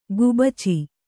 ♪ gubaci